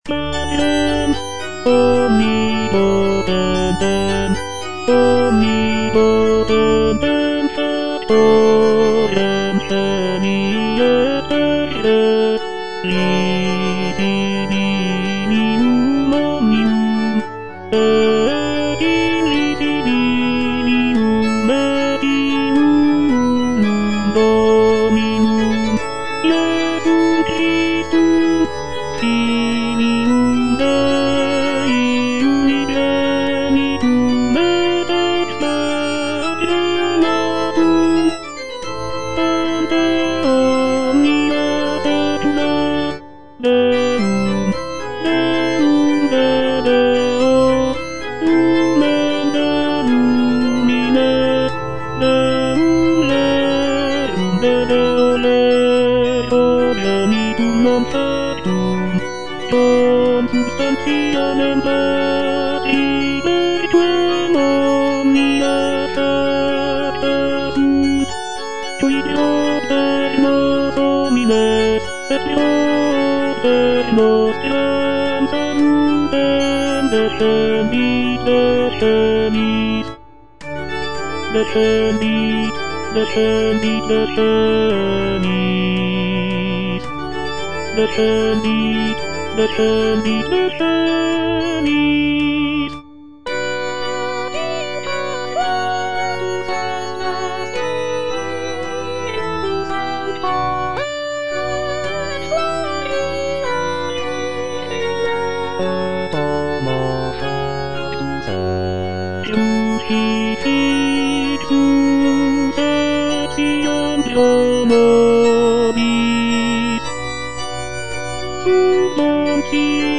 W.A. MOZART - MISSA BREVIS KV194 Credo - Tenor (Voice with metronome) Ads stop: auto-stop